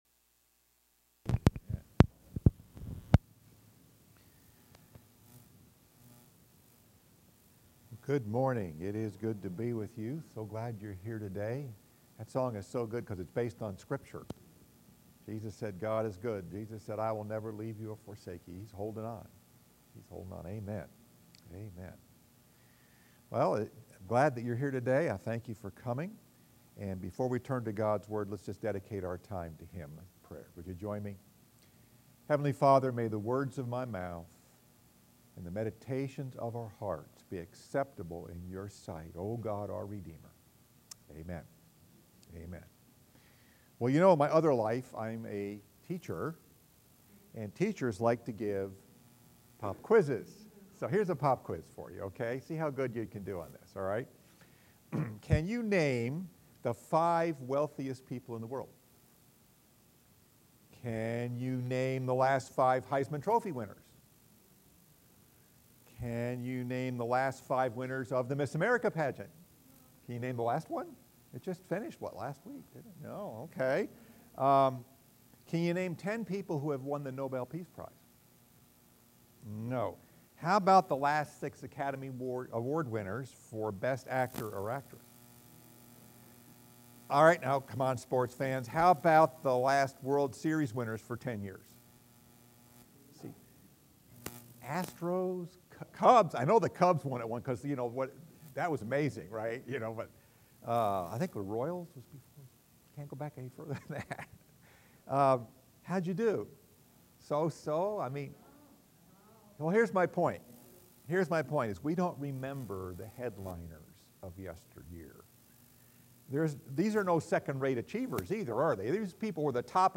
Message: “God’s Hall of Fame” , Part 2 Scripture: 1 Corinthians 1:26-31